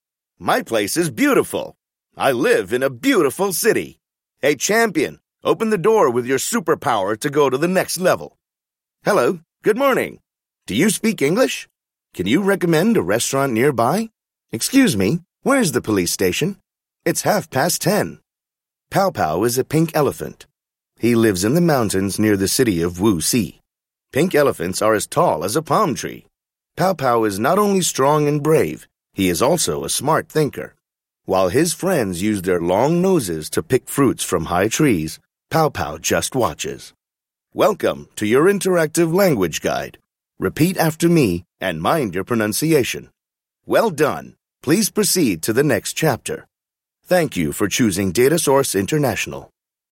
I am a native English speaker; my primary delivery is with a North American accent, and I quite often deliver projects using British English as well. Additionally, I am able to record from home using a professional microphone (RODE NT) and Audacity.
American, US, UK, British, English, Hindi, Indian accent, deep, medium, elder, adult, narration, audiobook, upbeat, drama, explainer, clear
Sprechprobe: eLearning (Muttersprache):